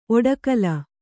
♪ oḍegallu